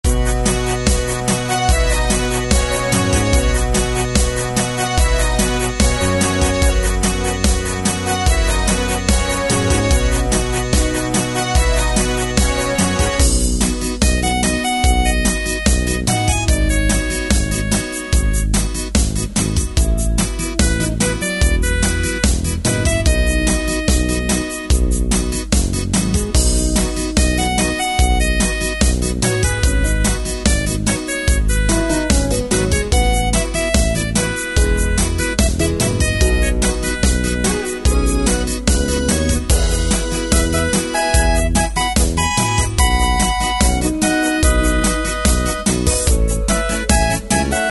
Tempo: 73 BPM.
MP3 with melody DEMO 30s (0.5 MB)zdarma